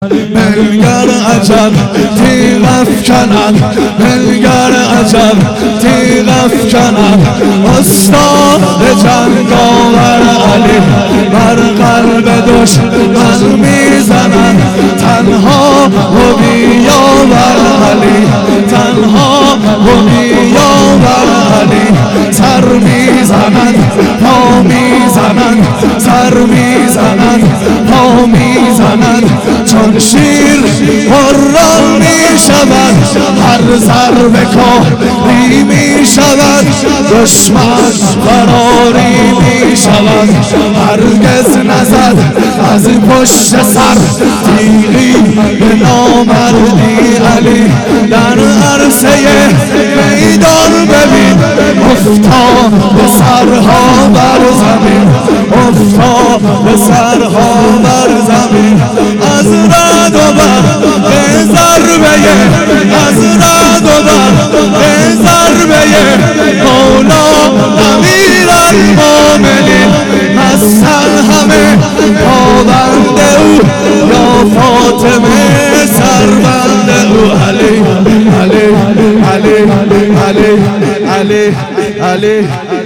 خیمه گاه - هیئت محبین صاحب الزمان (عج) - سخنرانی ها